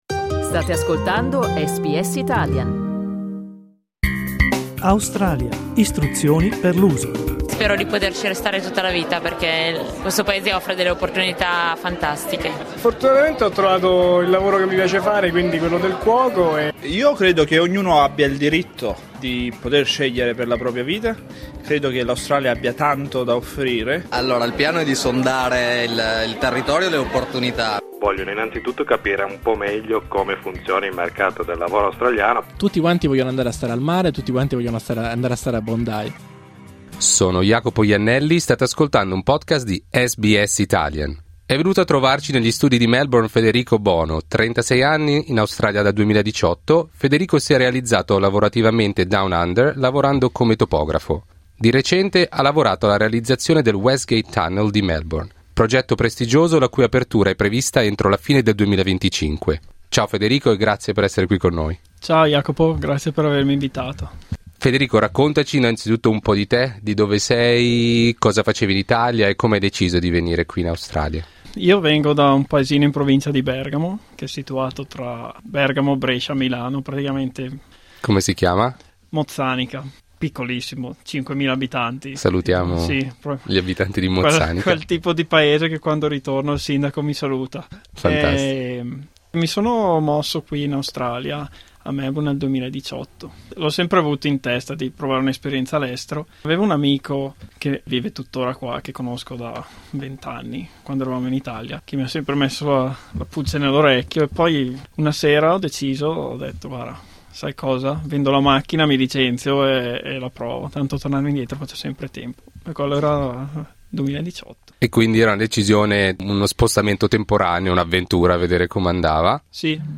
Clicca sul tasto "play" in alto per ascoltare l'intervista Nonostante sia arrivato in Australia parlando un inglese scolastico